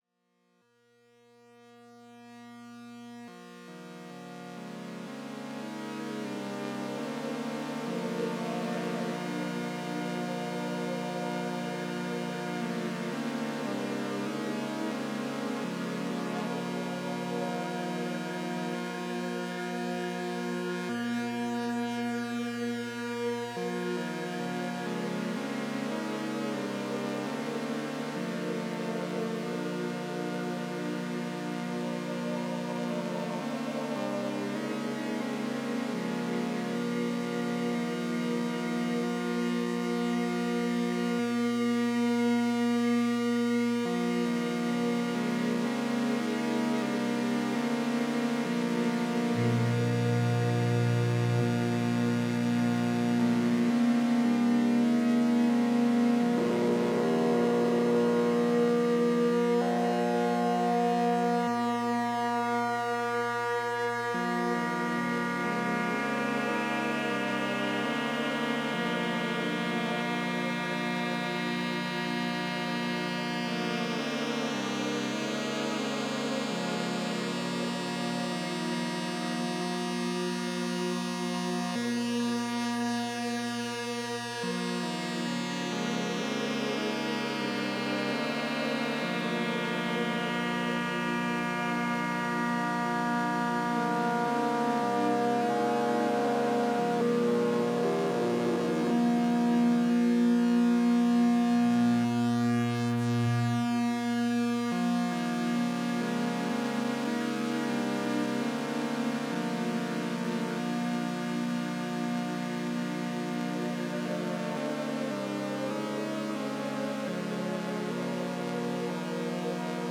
вон, поставил платс в chord, повесил ме-е-едленный синус на небольшой диапазон harmonics, aux через фильтр, зарядил в бигскай - пишу пост, а он мне в это время расчудесные потусторонние кружева плетет..